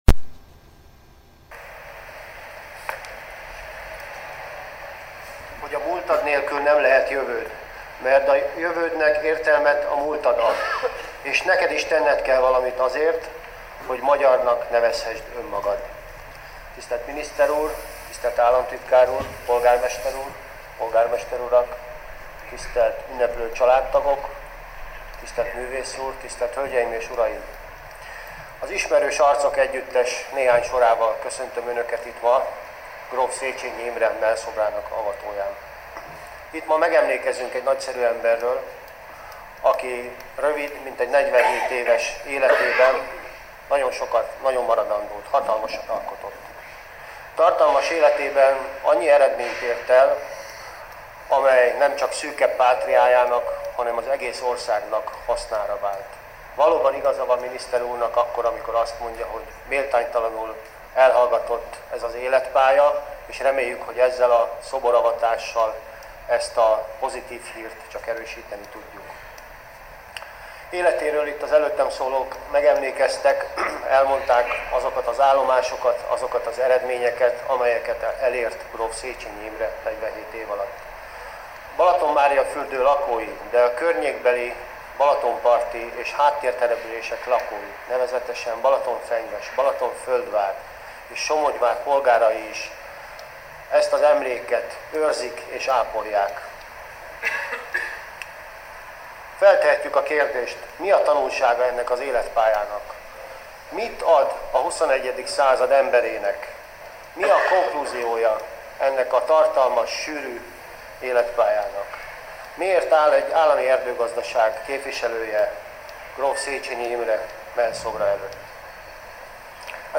Gróf Széchényi Imre mellszobrának felavatása a Földművelési Minisztérium árkádjai alatt lévő mezőgazdasági panteonban.